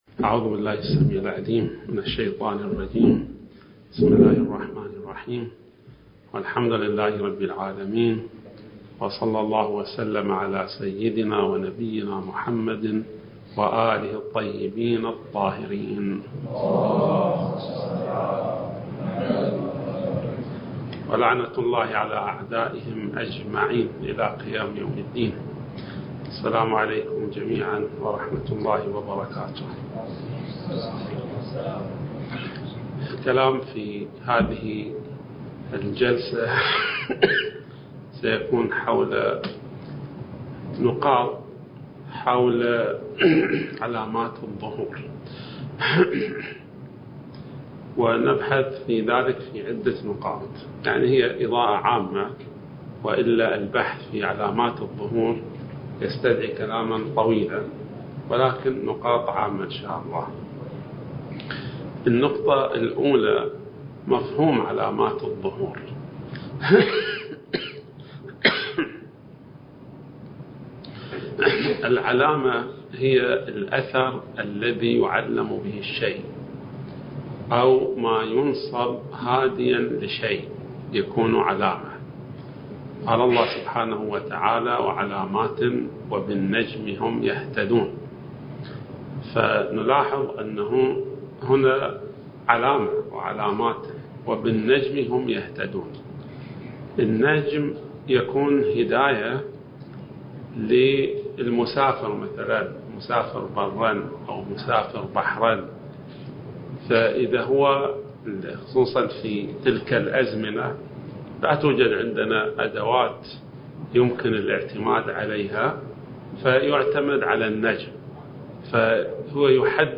(المحاضرة الخامسة والعشرون)
المكان: النجف الأشرف